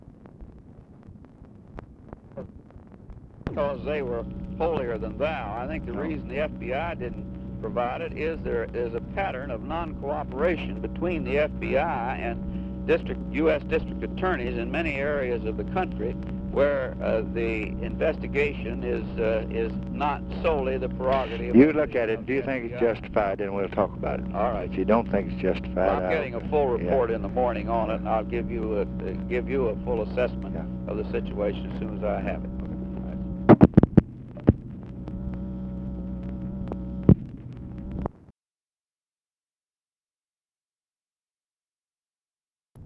Conversation with HENRY FOWLER, January 13, 1967
Secret White House Tapes